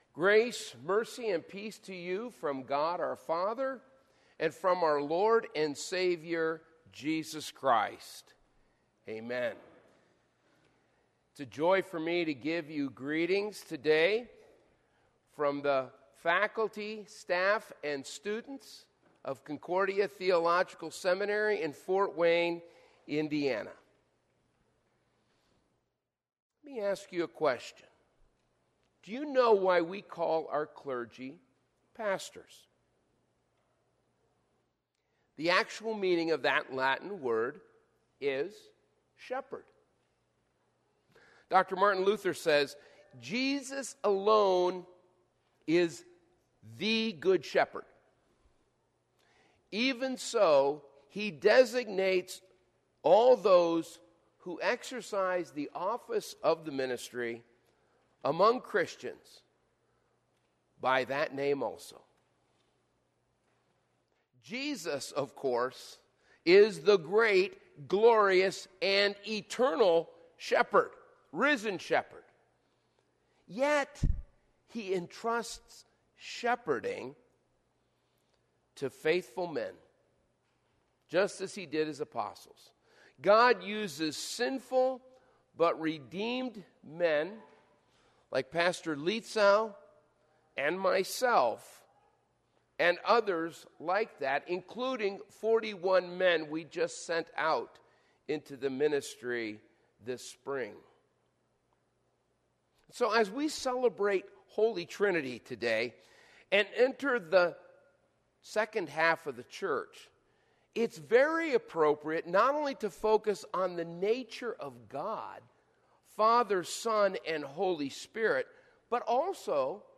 Sermon - 6/12/2022 - Wheat Ridge Lutheran Church, Wheat Ridge, Colorado
Trinity Sunday